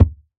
Minecraft Version Minecraft Version latest Latest Release | Latest Snapshot latest / assets / minecraft / sounds / block / packed_mud / step6.ogg Compare With Compare With Latest Release | Latest Snapshot
step6.ogg